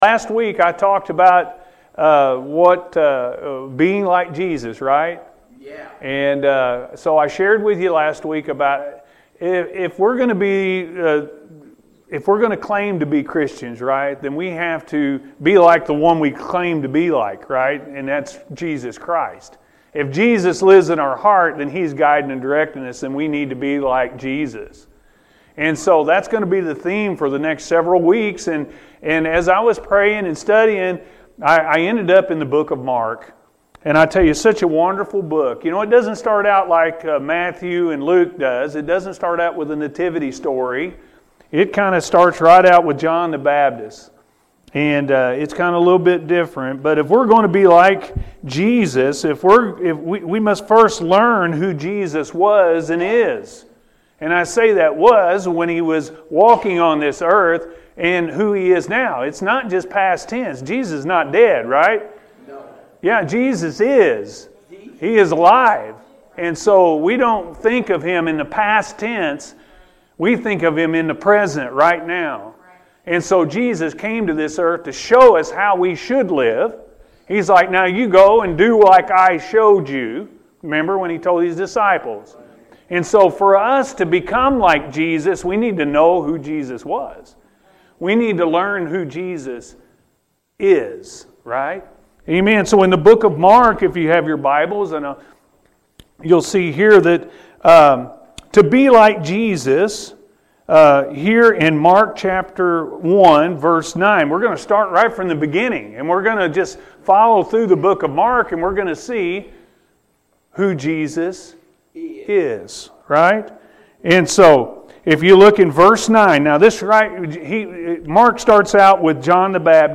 Being Led By The Spirit Of Christ-AM Service – Anna First Church of the Nazarene